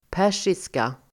Uttal: [p'är:siska]